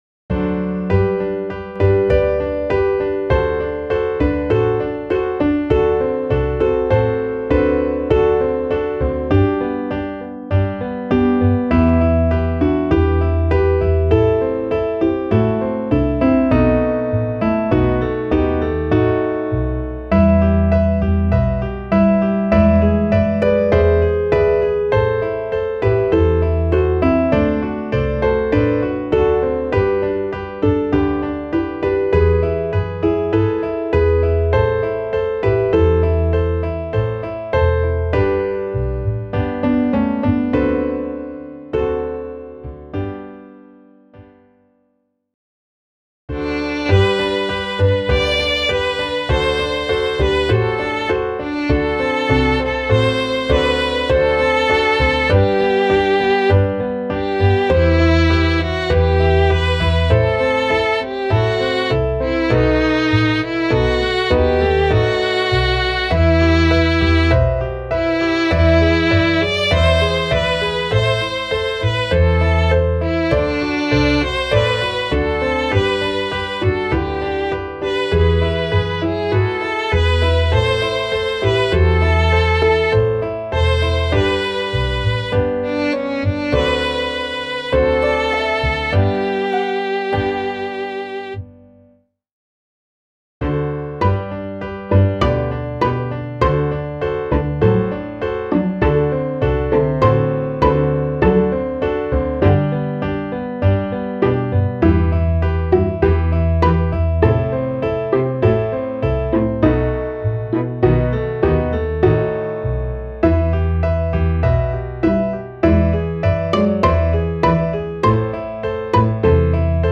Klaviersatz
midi_sommerglanz_klavier_320.mp3